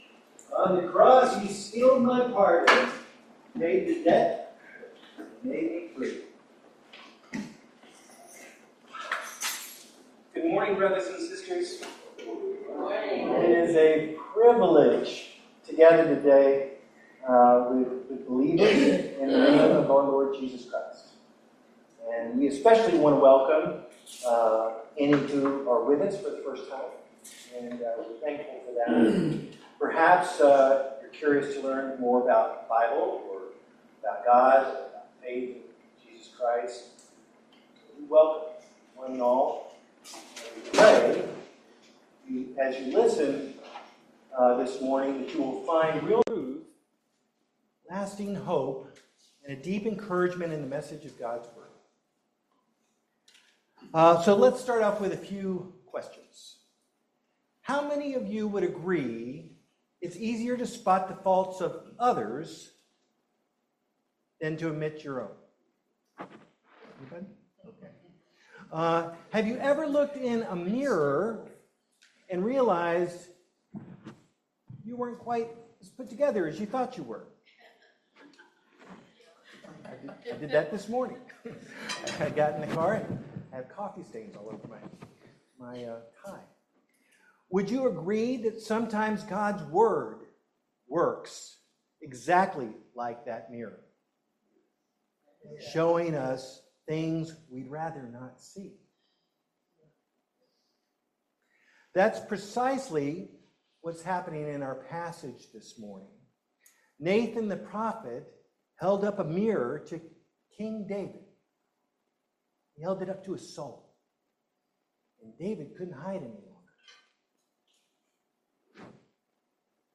Service Type: Family Bible Hour Topics: repentance , Mercy , Grace , Restoration